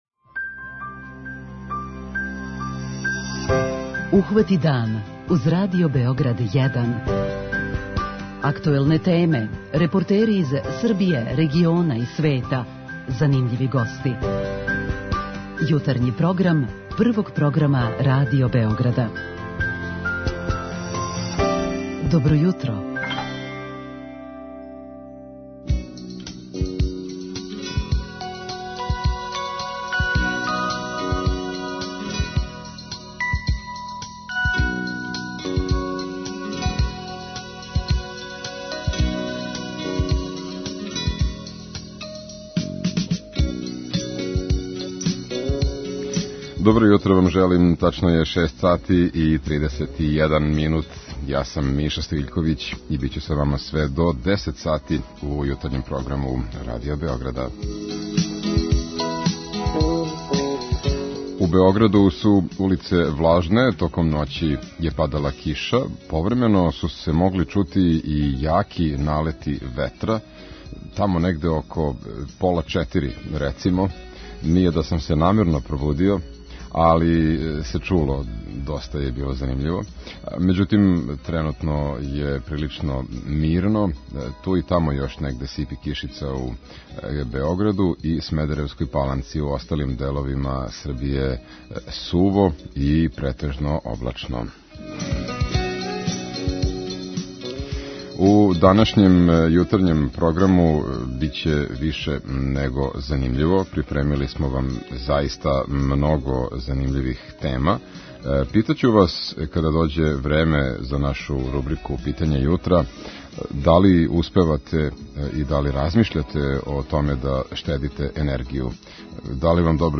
Као и сваког јутра, чућете најновије сервисне информације и много добре музике.